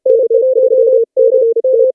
60hz.wav